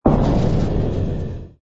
engine_ci_fighter_kill.wav